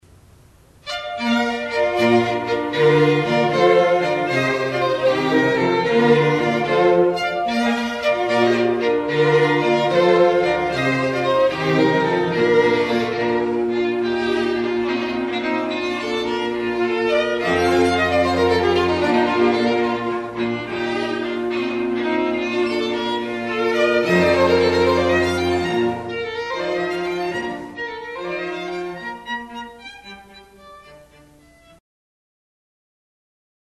This Baltimore String Quartet is a professional string ensemble providing chamber music for wedding ceremonies, receptions, private parties, and other events since 1978.
The range of musical styles covers classical and popular music.